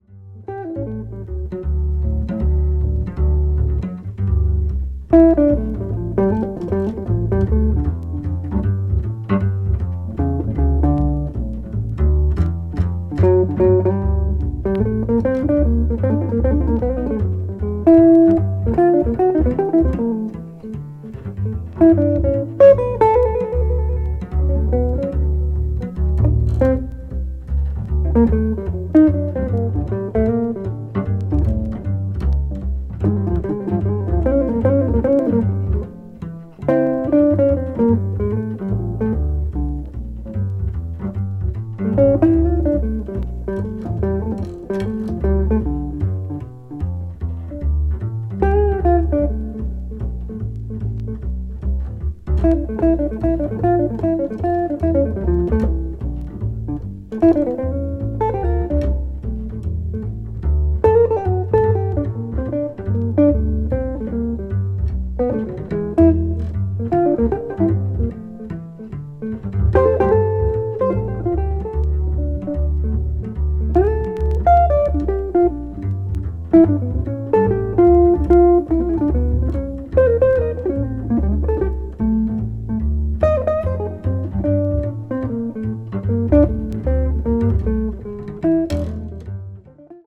contemporary jazz   jazz standard   modern jazz